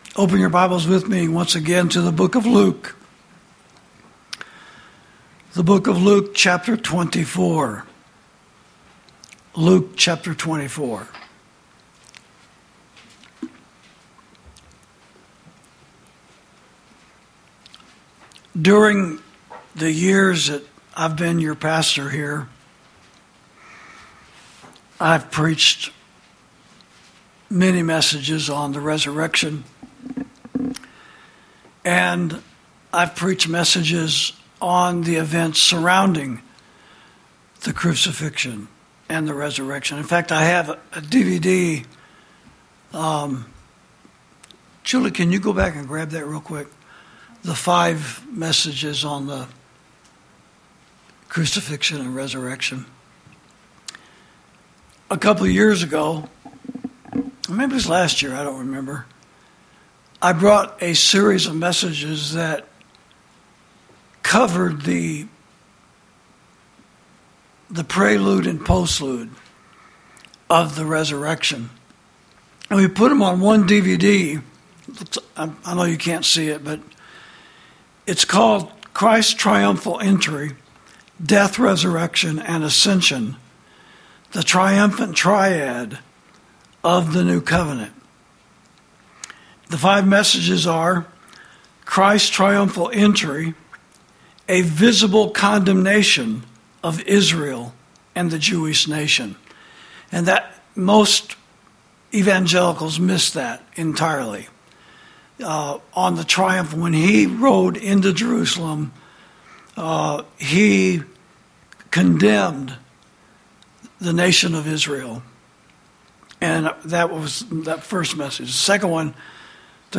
Sermons > The Road To Emmaus